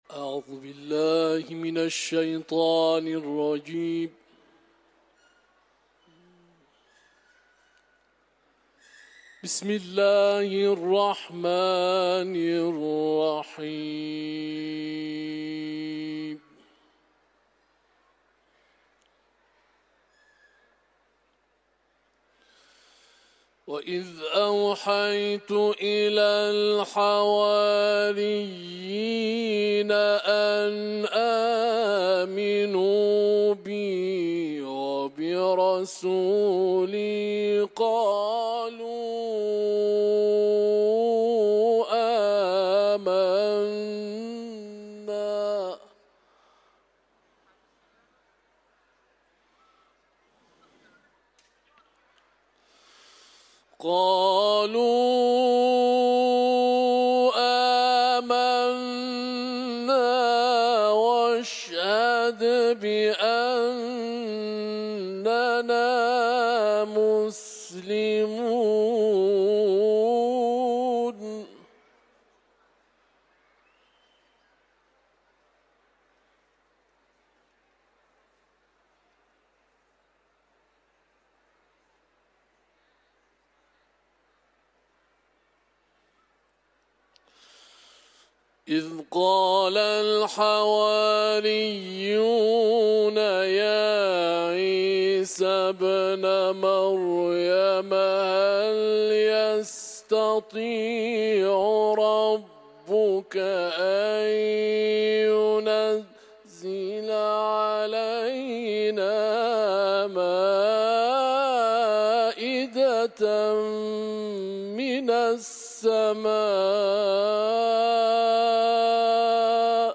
Kur'an-ı Kerim
Etiketler: İranlı kâri ، Kuran tilaveti ، Maide sûresi